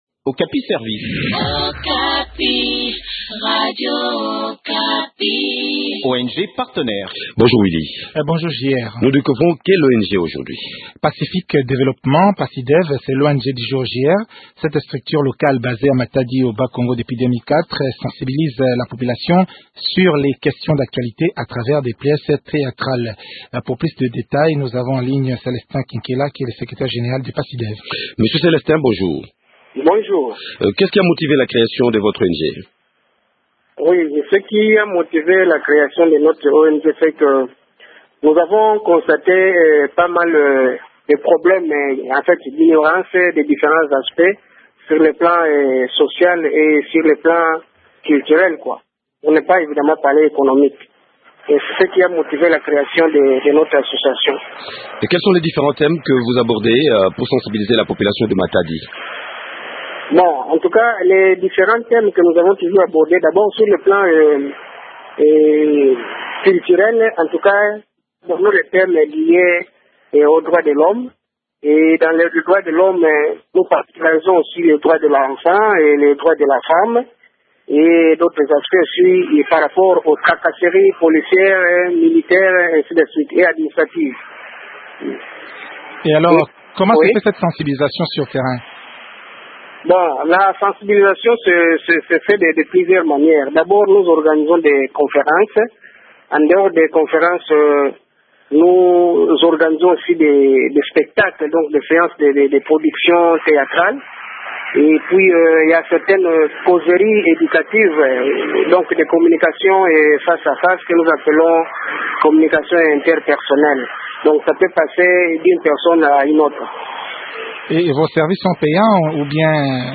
fait le point de leurs activités au micro